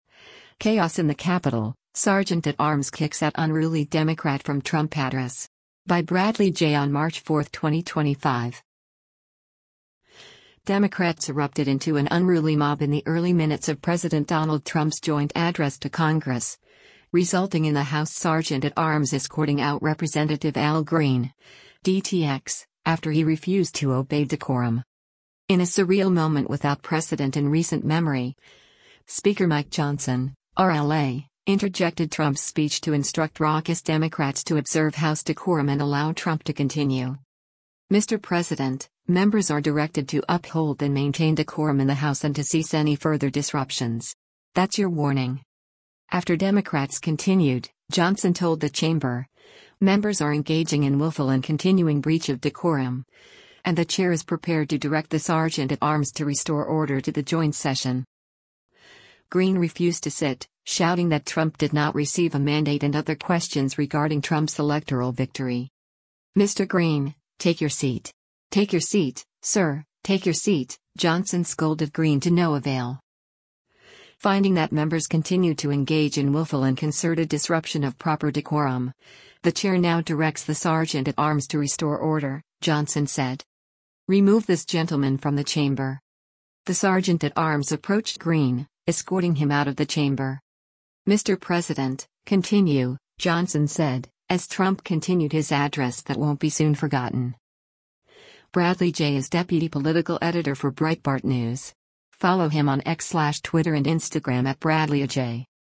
Democrats erupted into an unruly mob in the early minutes of President Donald Trump’s joint address to Congress, resulting in the House Sergeant at Arms escorting out Rep. Al Green (D-TX) after he refused to obey decorum.
In a surreal moment without precedent in recent memory, Speaker Mike Johnson (R-LA) interjected Trump’s speech to instruct raucous Democrats to observe House decorum and allow Trump to continue.
Green refused to sit, shouting that Trump did not receive a mandate and other questions regarding Trump’s electoral victory.